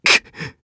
Clause-Vox_Sad_1_jp.wav